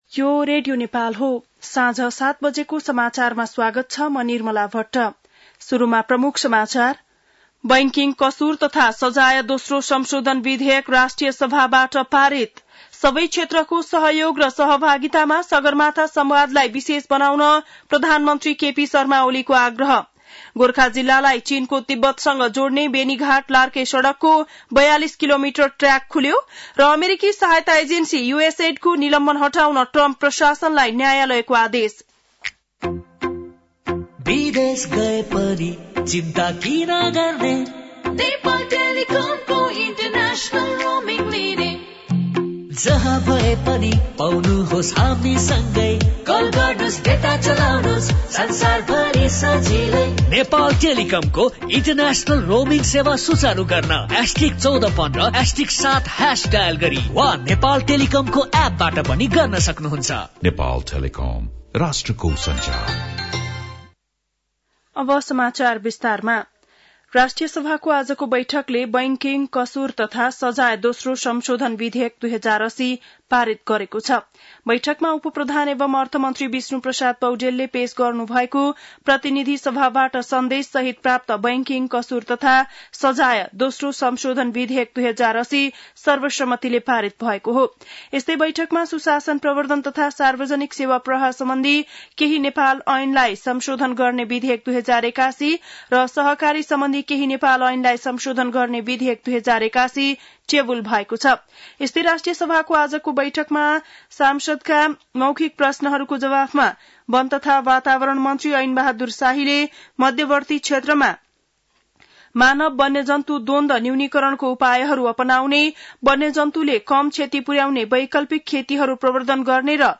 बेलुकी ७ बजेको नेपाली समाचार : ६ चैत , २०८१